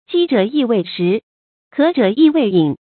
jī zhě yì wéi shí，kě zhě yì wéi yǐn
饥者易为食，渴者易为饮发音